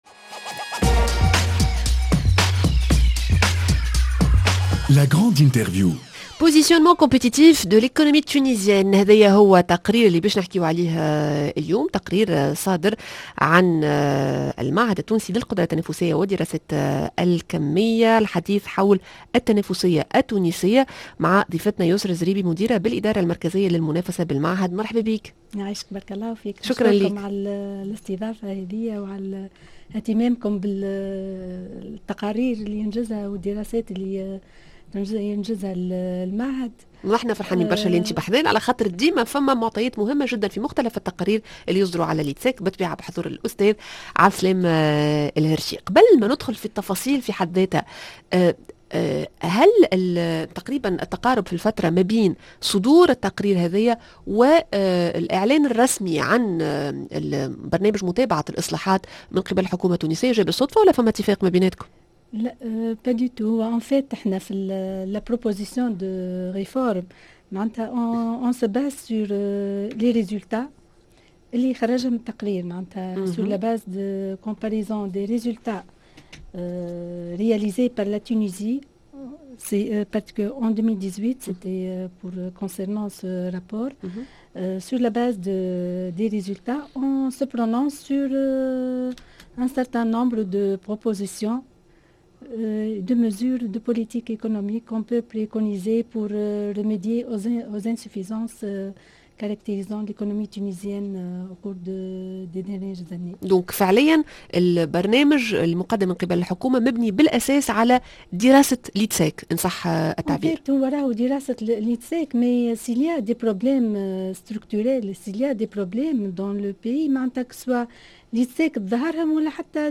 La grande interview